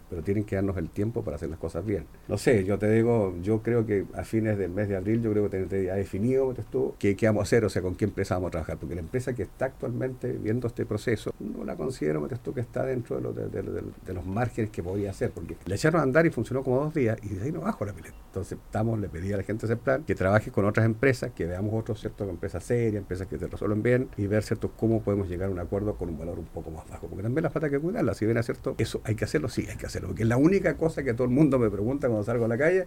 alcalde-pileta-osorno.mp3